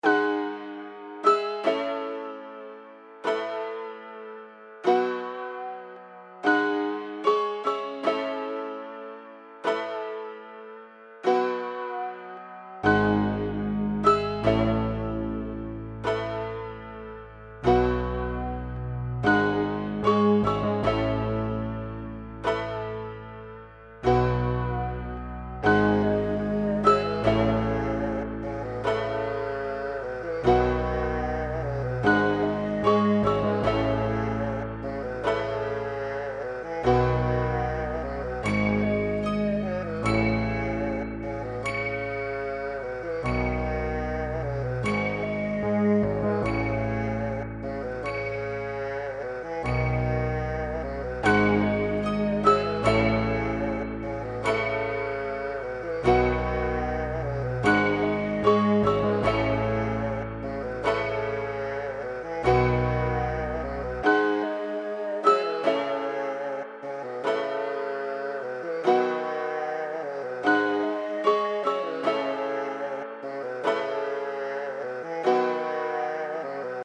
•合成器，琶音，打击垫，低音合成器+茂密的纹理（大和小）
•所有音频都记录在1/4英寸的线对带LYREC PTR-1上，以保暖
•采用立体声WAV 16/44格式